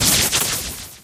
belle_dryfire_01.ogg